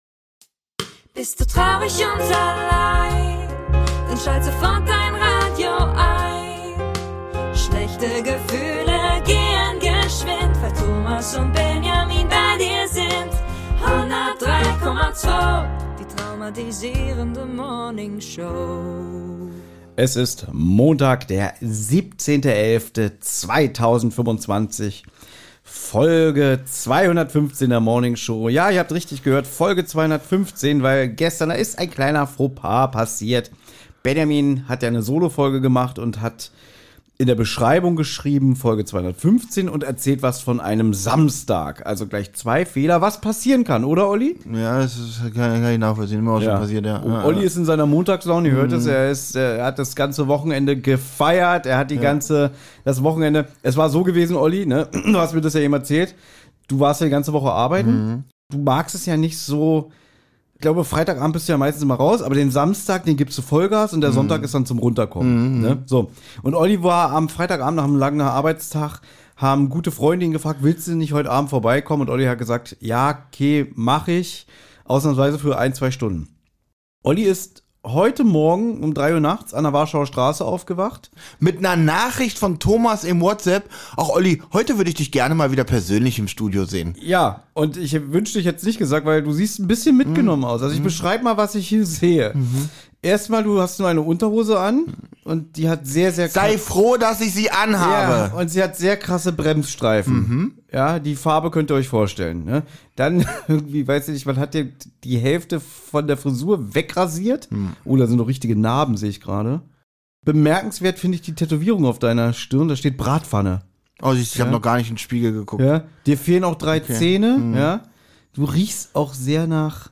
Neues von der A 100 gibt´s auch und 2 gutgelaunte Podcaster!